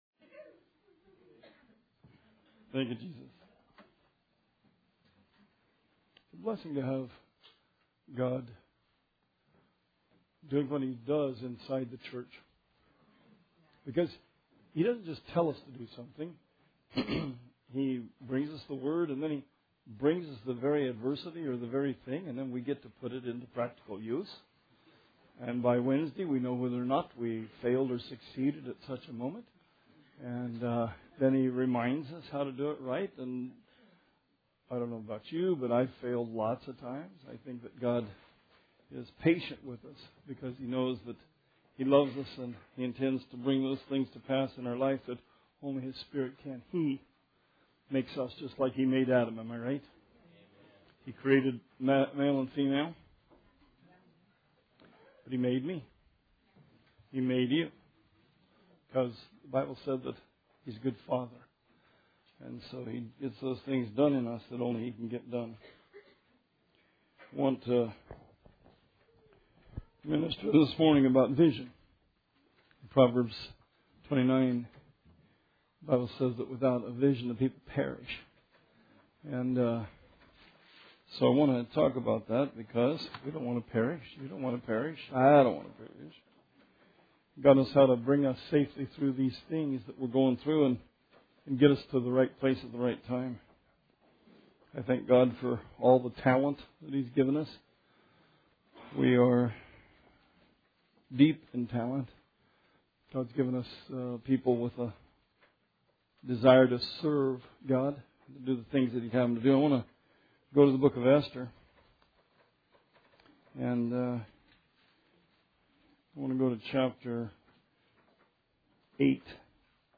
Sermon 11/20/16